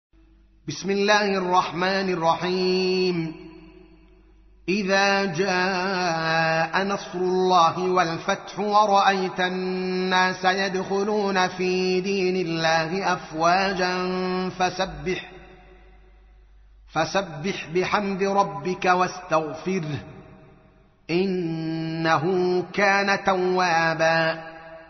تحميل : 110. سورة النصر / القارئ الدوكالي محمد العالم / القرآن الكريم / موقع يا حسين